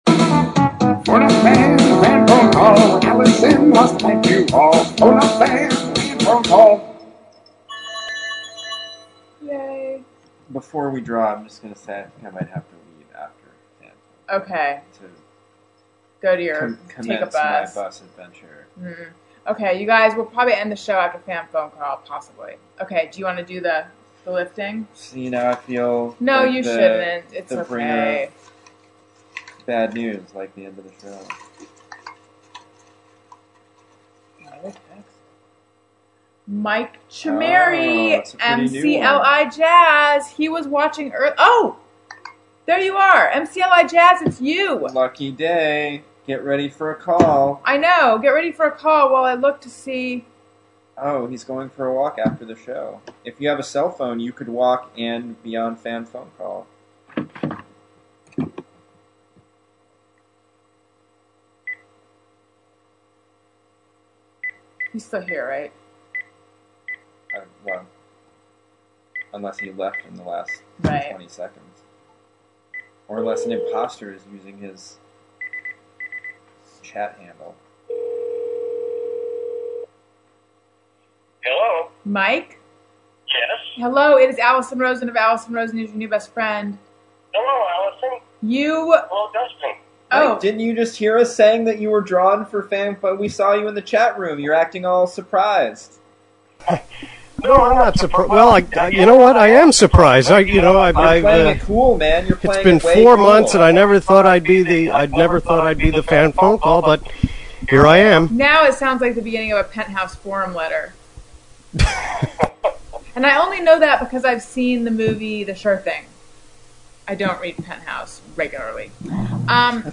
Fan phone call!
I also recorded my end of the conversation in Adobe Audition with my mic in hand.